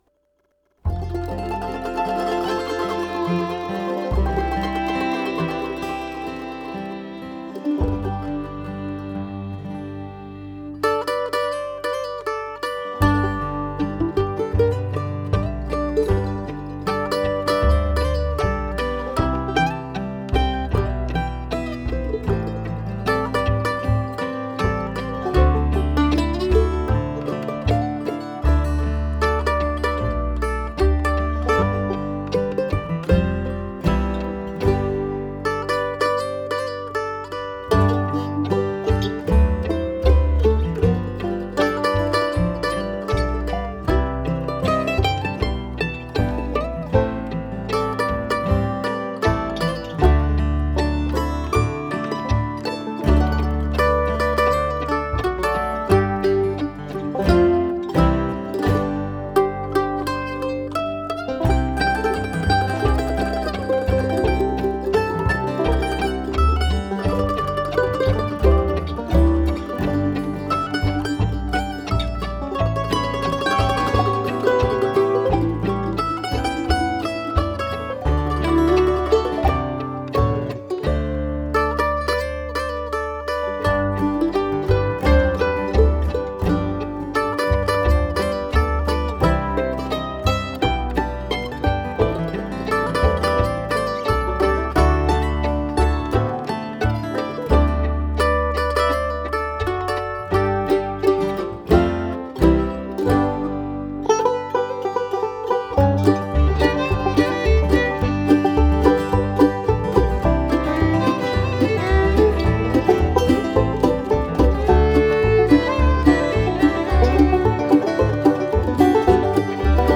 mandolin
fiddle
banjo